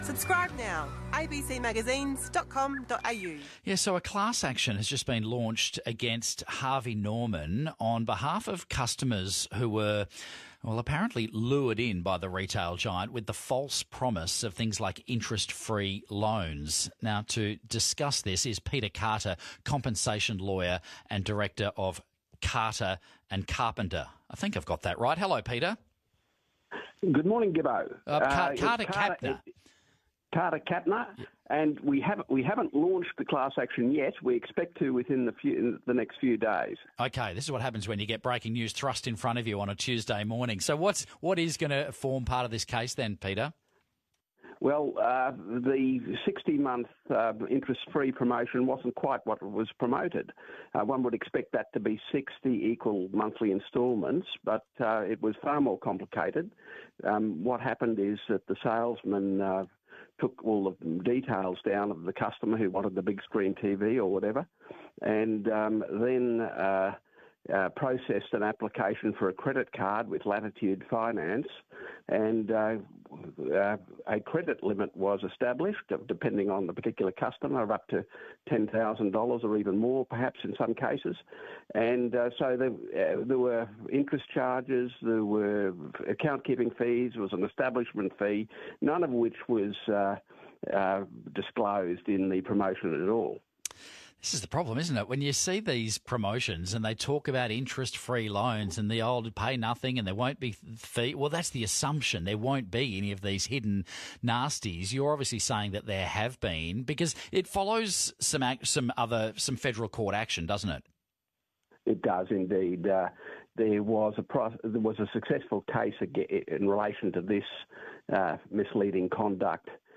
ABC-Radio-Perth-9-Sep-2025.mp3